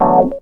HOUSE 6-R.wav